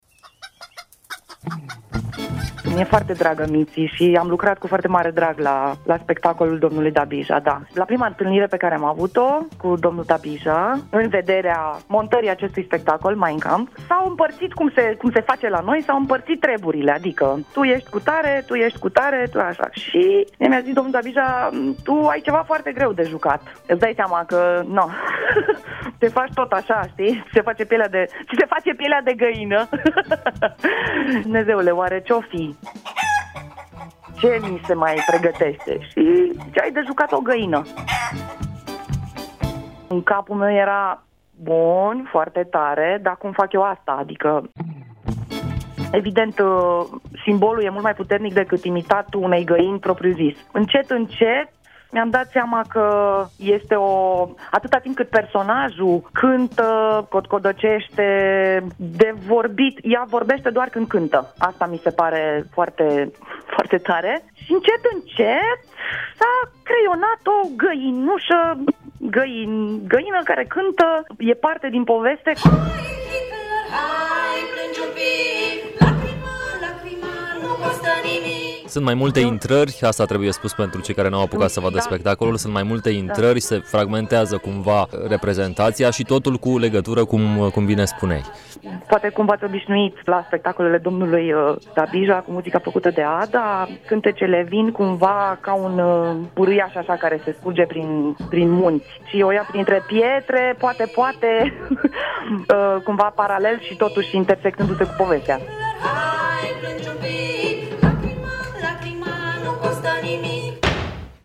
Într-un interviu acordat Europa FM